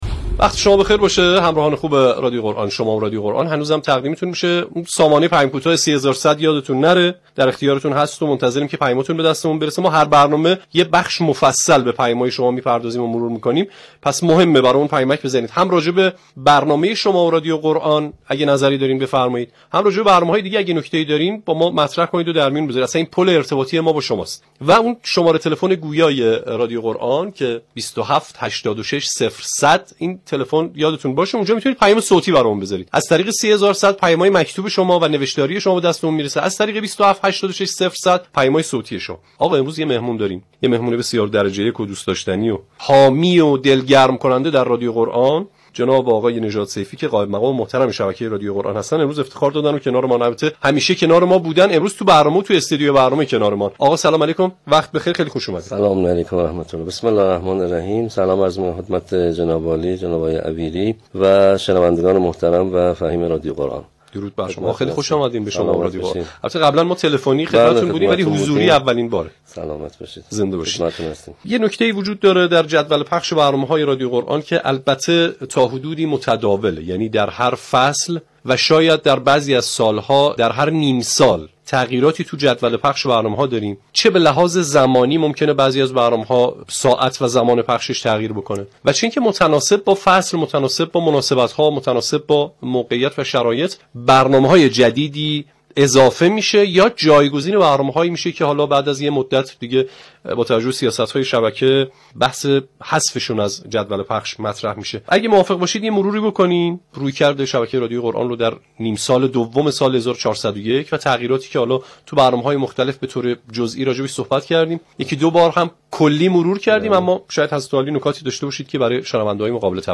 پخش فرازهایی از تلاوت‌های ناب